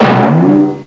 TYMPANI.mp3